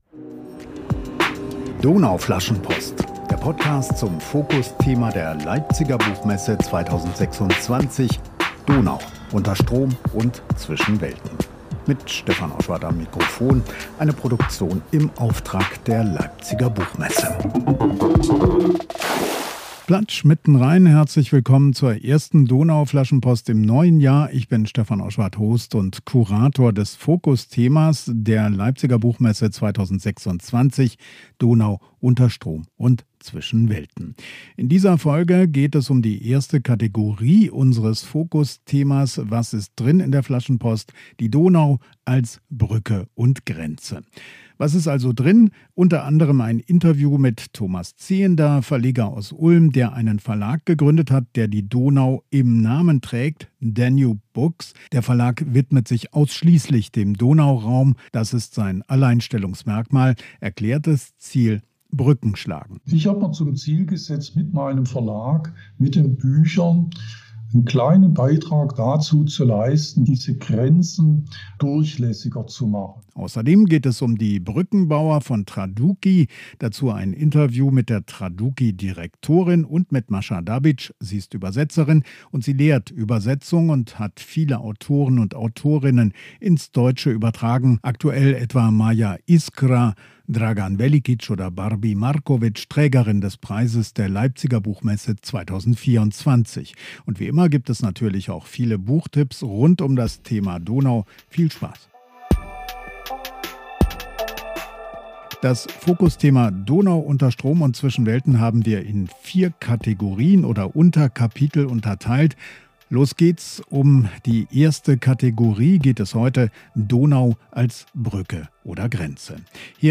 Was ihn daran reizt, wer ihm hilft, Autor/inn/en zu finden und welche Themen sie beschäftigen - das hat er mir im Interview erzählt.